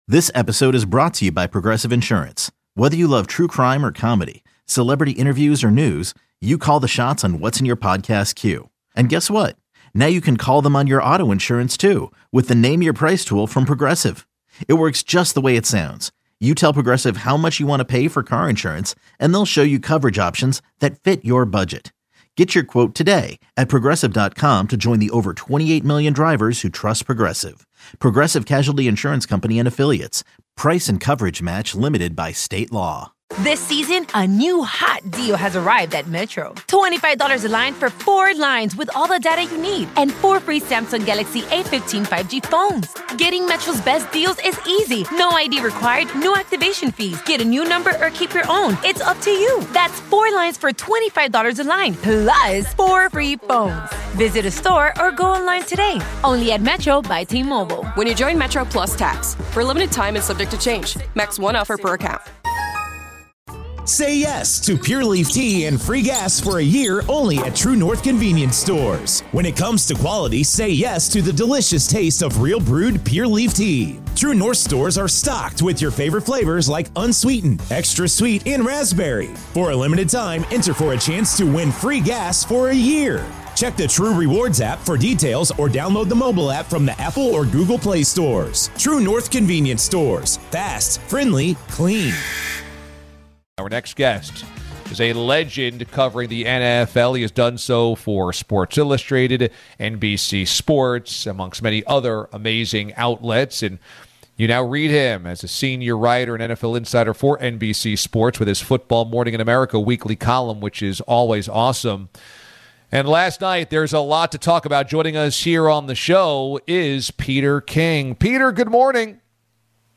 The DA Show Interviews